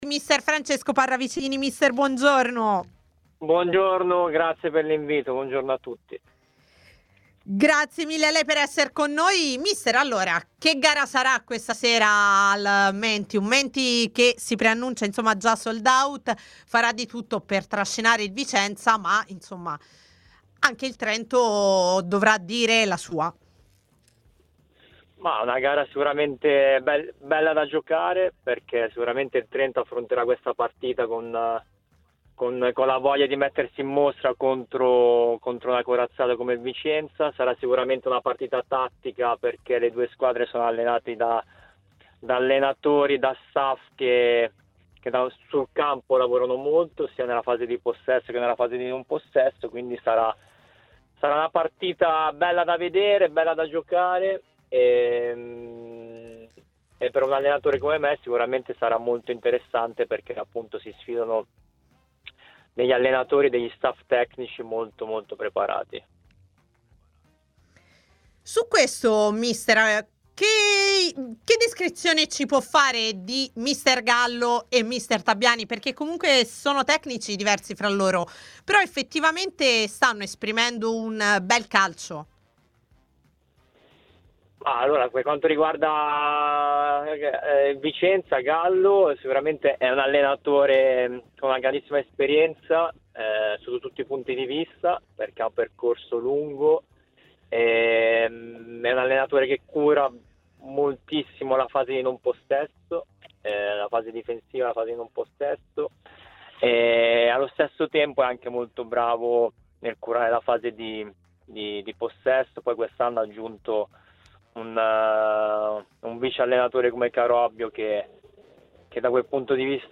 TMW Radio